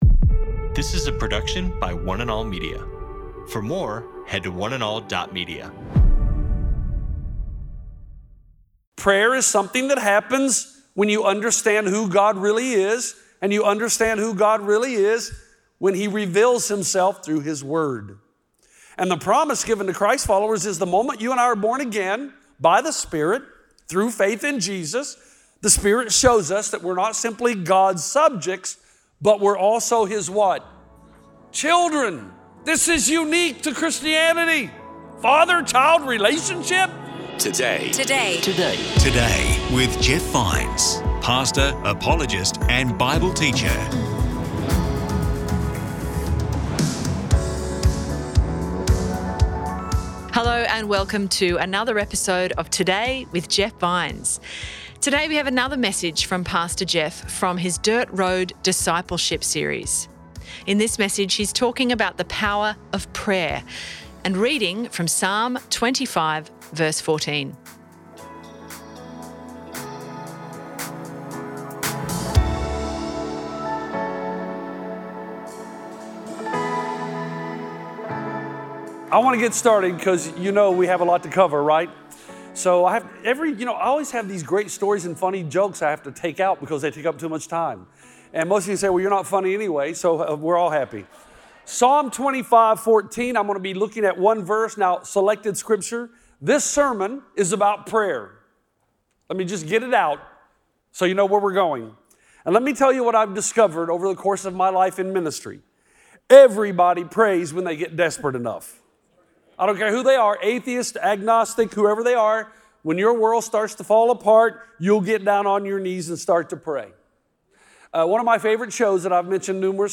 In this message he’s talking about the power of prayer, and reading from PSALM 25:14